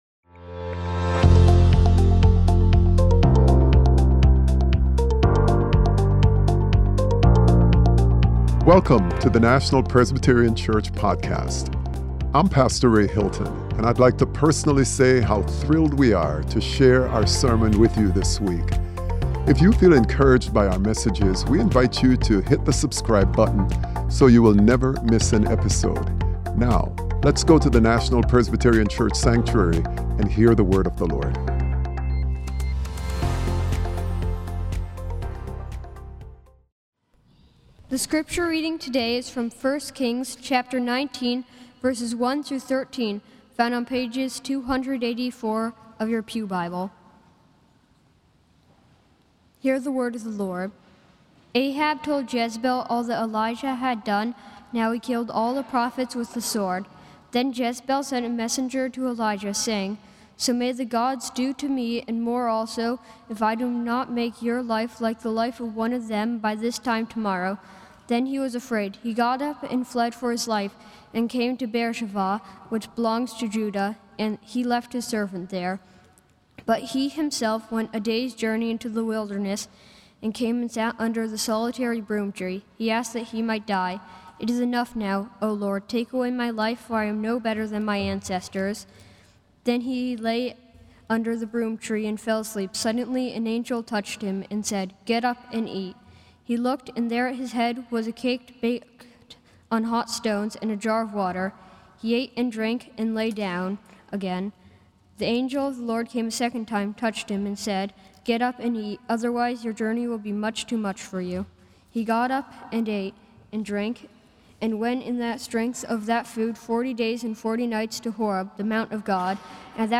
Sermon: Encountering God More Deeply - God's Still, Small Voice - National Presbyterian Church